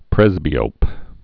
(prĕzbē-ōp, prĕs-)